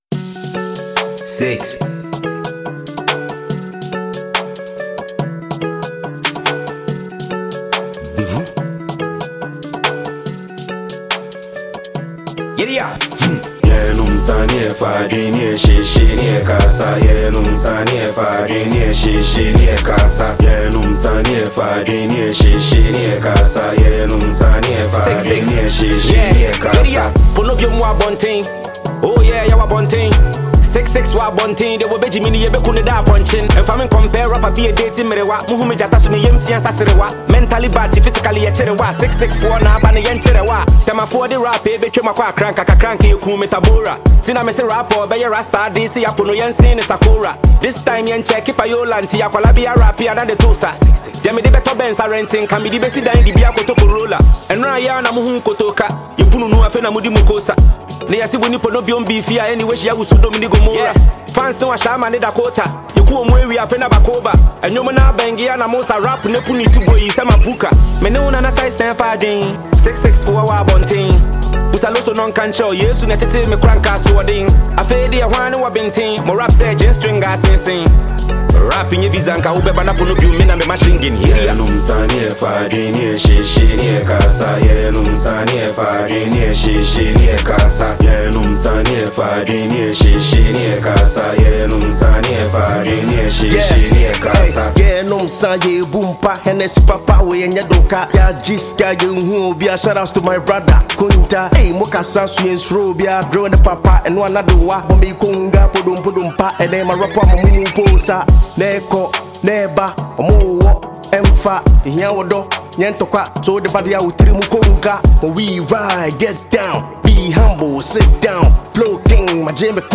Ghana MusicMusic
Ghanaian heavyweight rapper